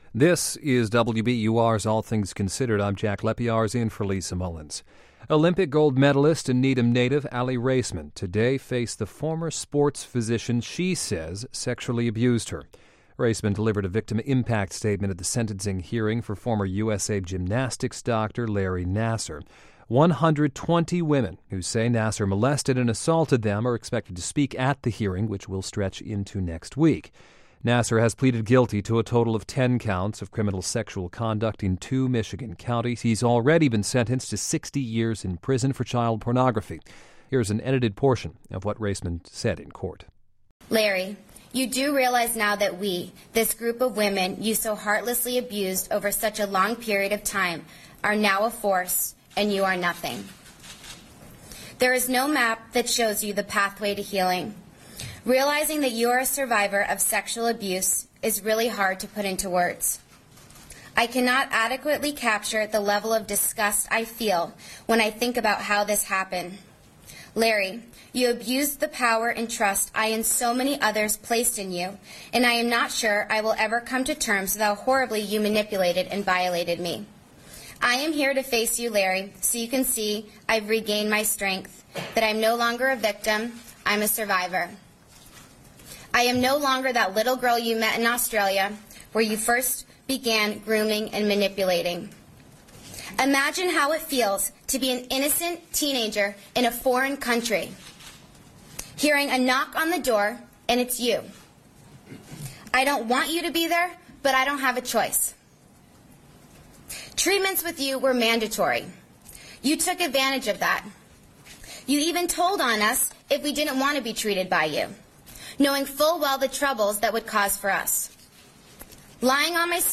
Speaking in a clear and firm voice, Raisman read her victim impact statement before the court on Friday morning, castigating Nassar and calling him a "monster."
news_0119_aly-raisman-statement-nassar-trial.mp3